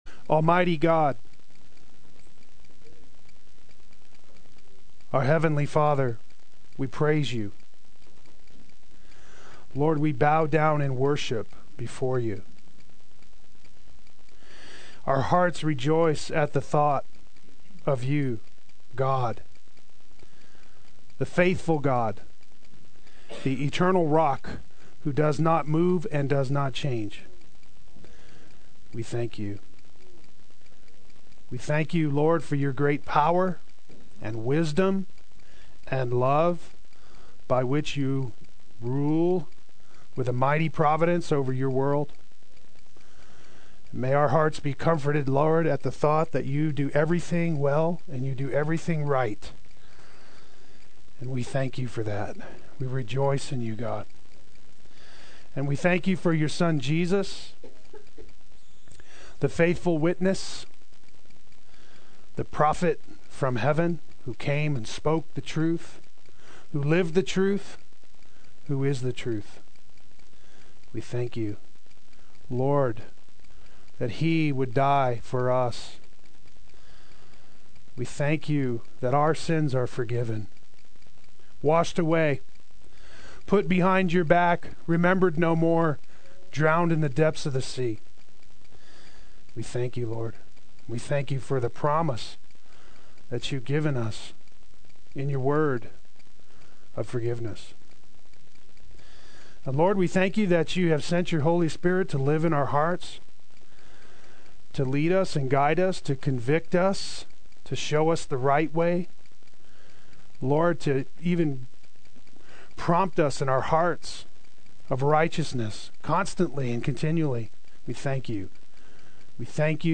Play Sermon Get HCF Teaching Automatically.
Great is Thy Faithfulness Adult Sunday School